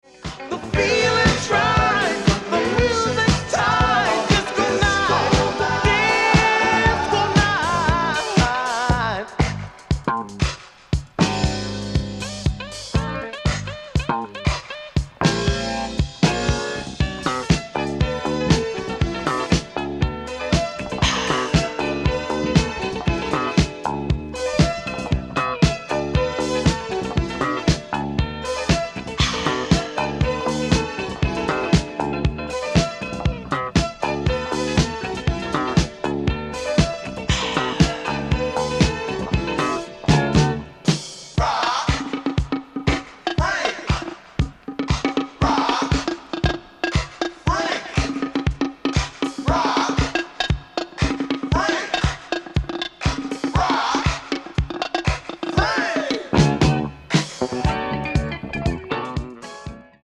Boogie. Disco. Electro